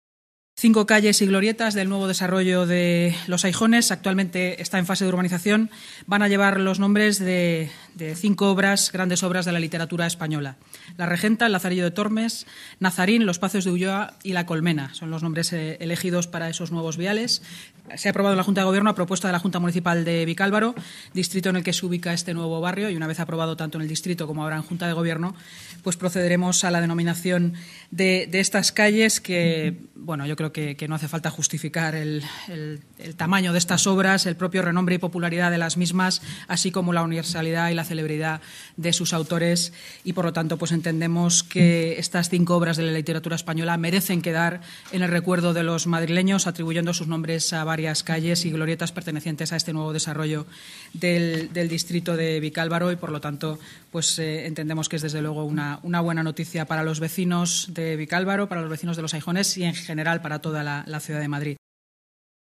Nueva ventana:Declaraciones de la portavoz municipal, Inmaculada Sanz: